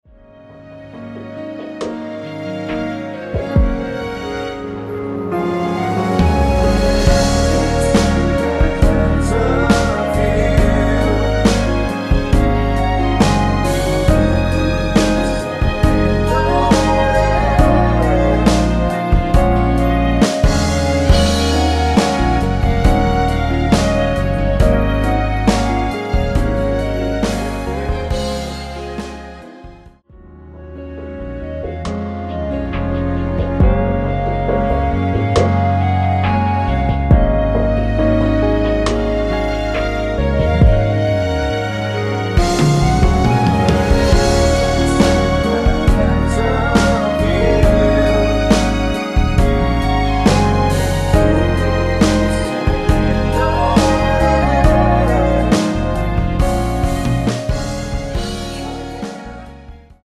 원키에서(-2)내린 멜로디와 코러스 포함된 MR입니다.(미리듣기 확인)
Db
앞부분30초, 뒷부분30초씩 편집해서 올려 드리고 있습니다.